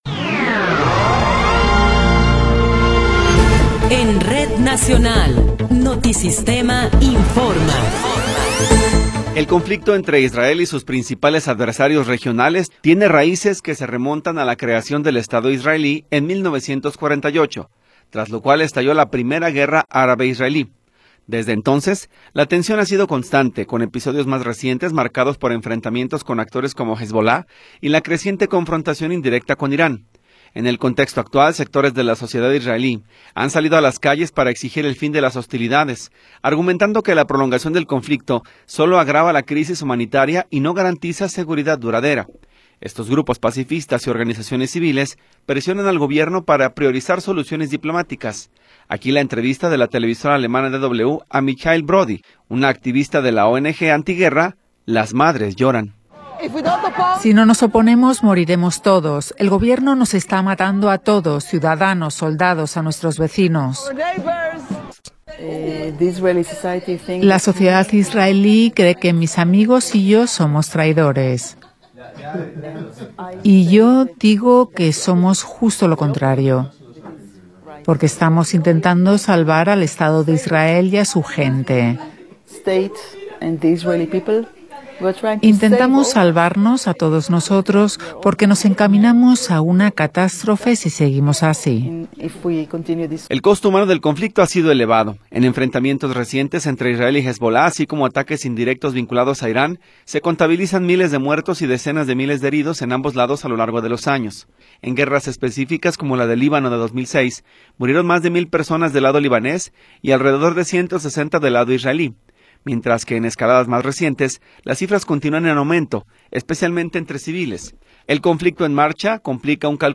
Noticiero 16 hrs. – 26 de Abril de 2026
Resumen informativo Notisistema, la mejor y más completa información cada hora en la hora.